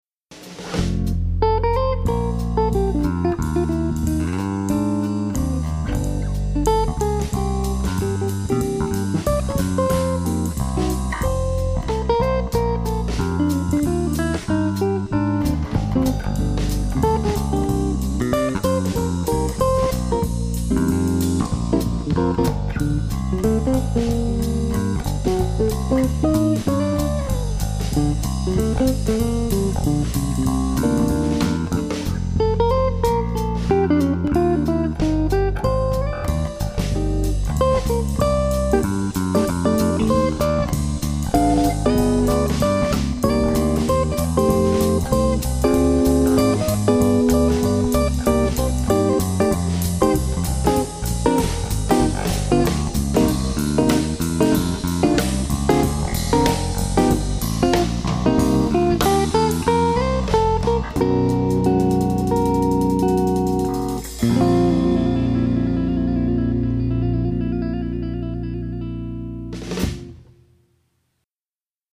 Guitar, Bass & Drums